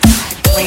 dtw snare (needs cleaning).mp3